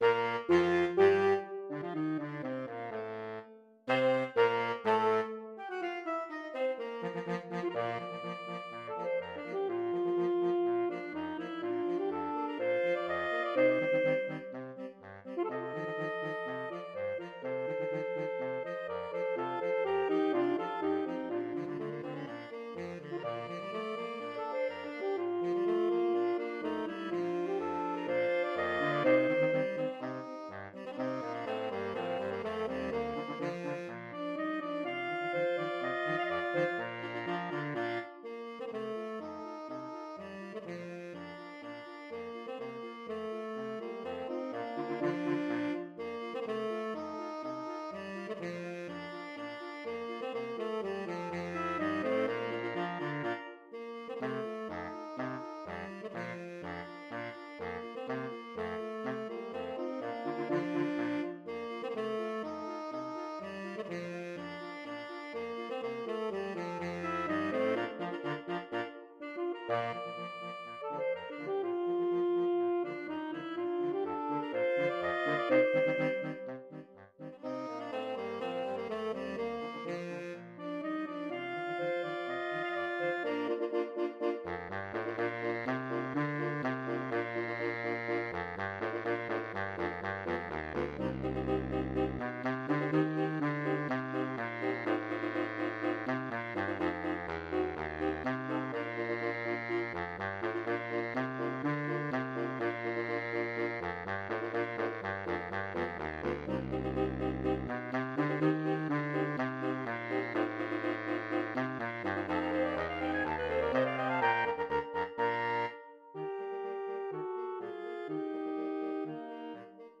arranged for Saxophone Quartet
Spanish March
Saxophone Quartet with flexible scoring Format